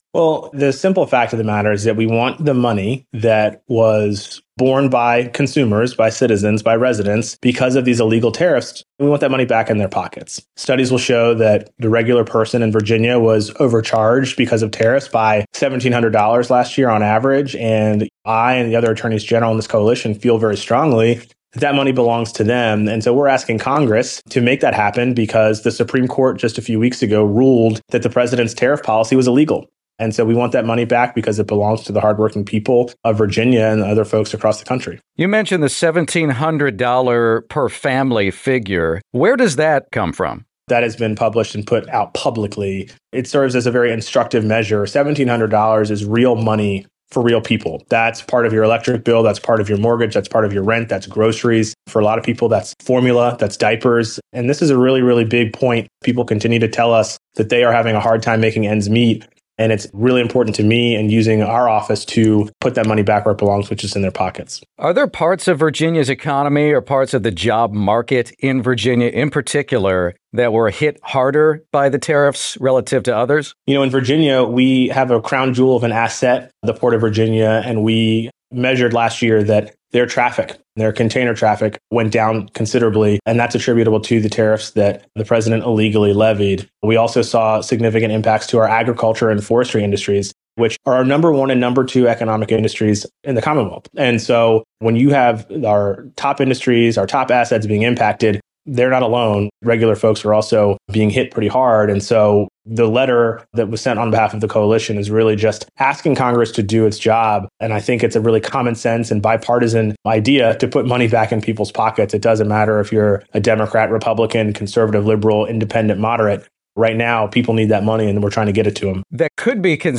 Virginia Attorney General Jay Jones on push for tariff refunds